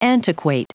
Prononciation du mot antiquate en anglais (fichier audio)
Prononciation du mot : antiquate